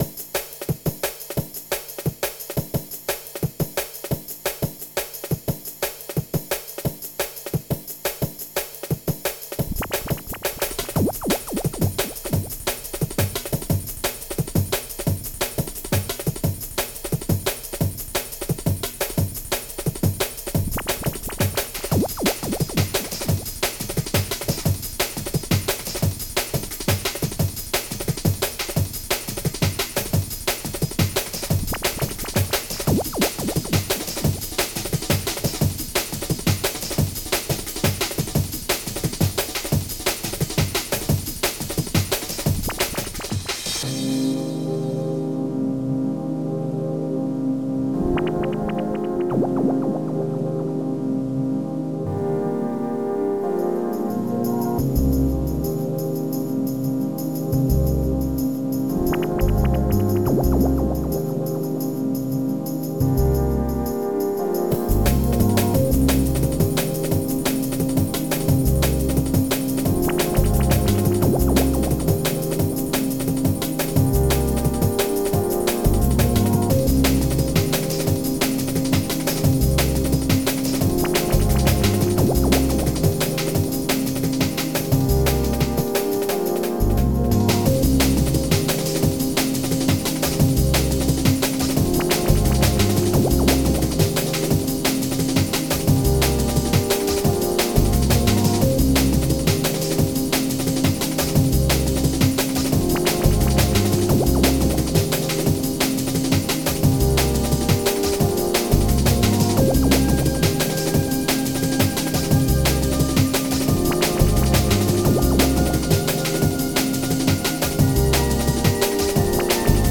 xm (FastTracker 2 v1.04)
at mekka symposium